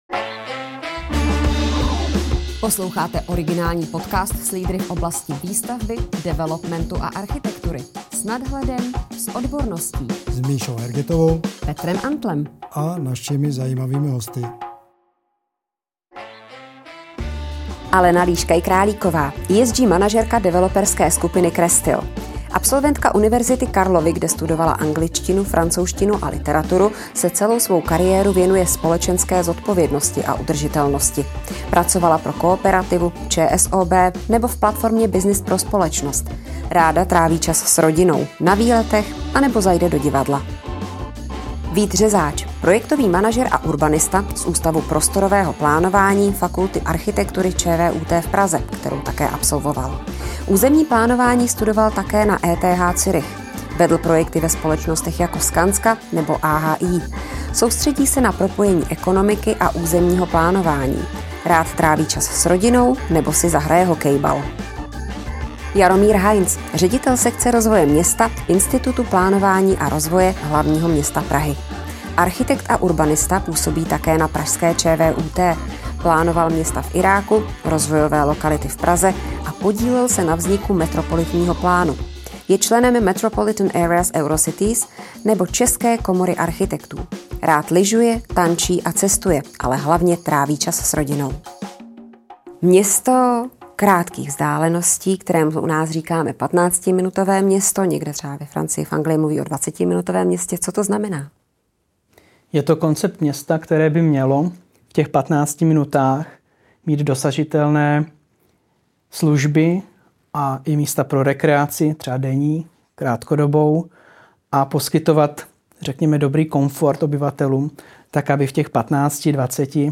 O přínosech konceptu a nutných změnách výstavby i dopravy se dozvíte v diskuzi. Dopravně-urbanistický koncept kompaktních měst vzbudil pozornost teprve nedávno. Kde a jak vznikl a co městům přináší?